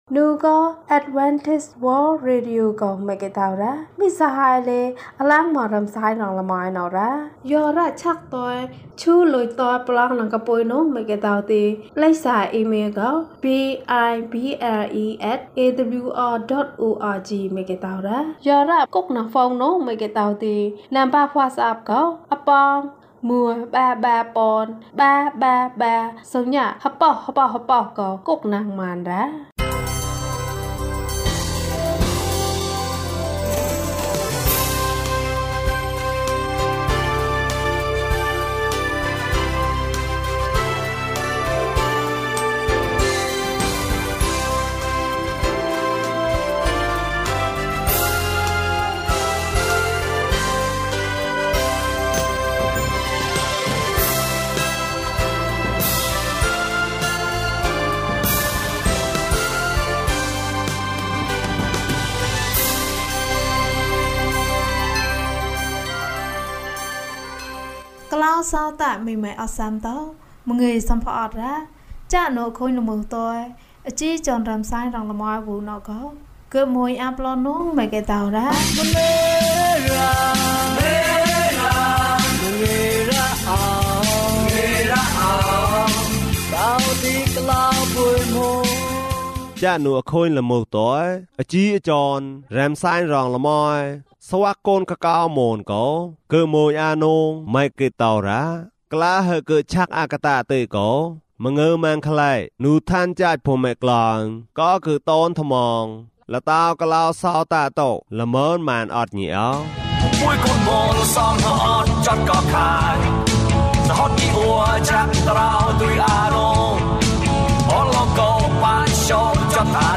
ခရစ်တော်ထံသို့ ခြေလှမ်း။၀၆ ကျန်းမာခြင်းအကြောင်းအရာ။ ဓမ္မသီချင်း။ တရားဒေသနာ။